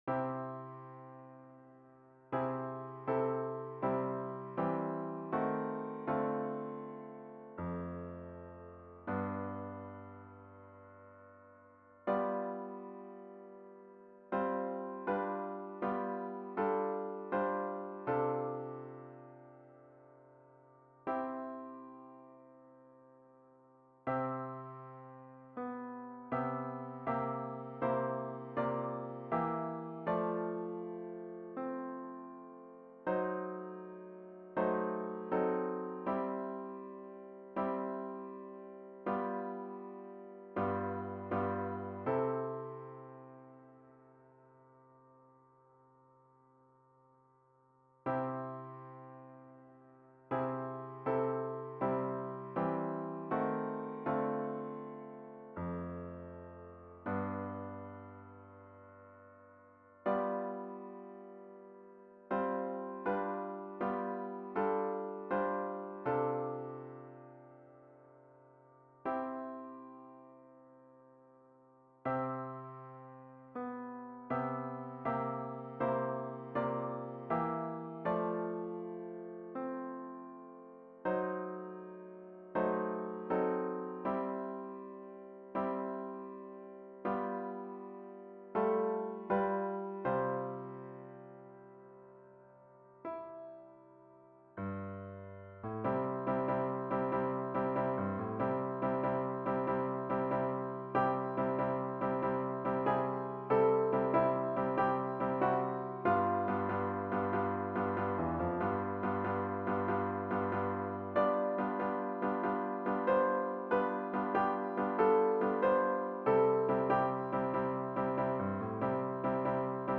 Three verses - three styles.